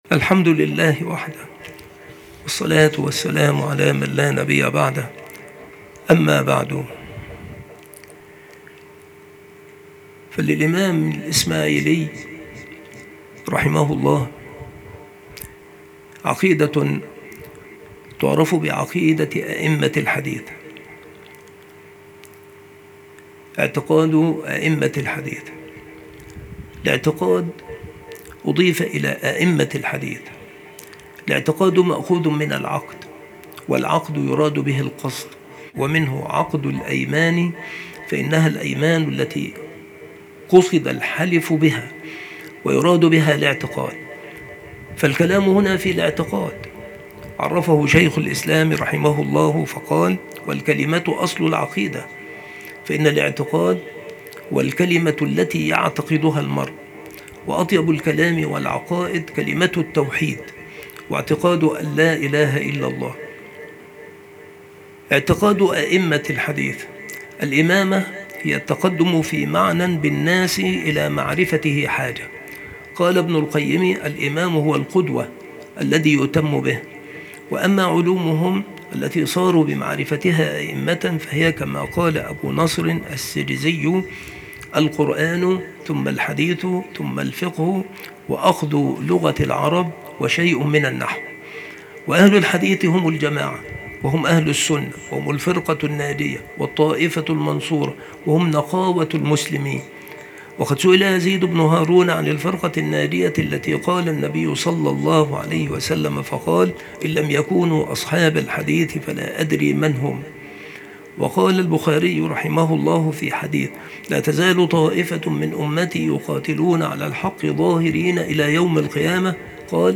المكتبة - سبك الأحد - أشمون - محافظة المنوفية - مصر